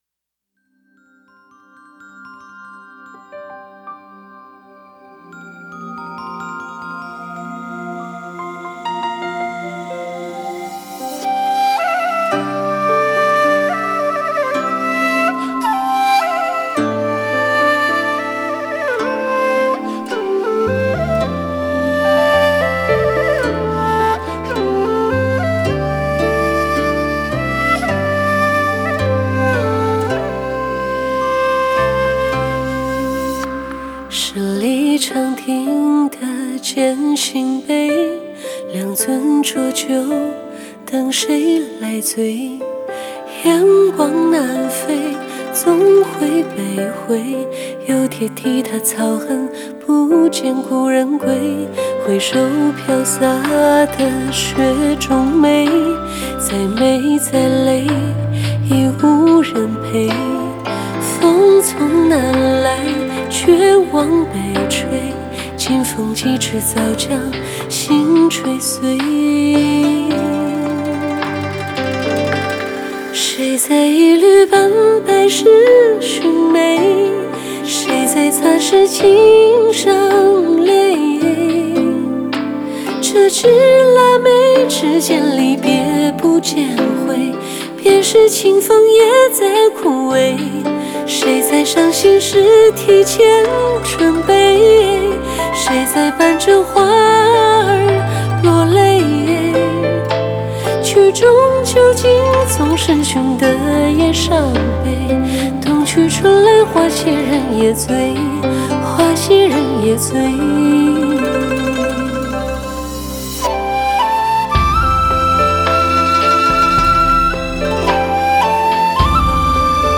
在线试听为压缩音质节选，体验无损音质请下载完整版